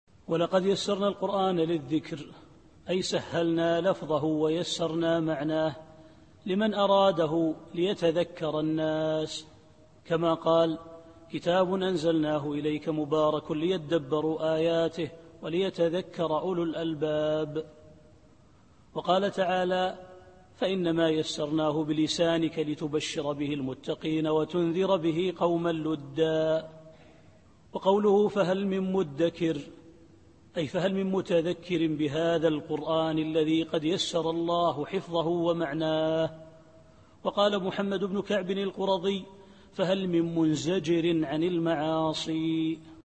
التفسير الصوتي [القمر / 17]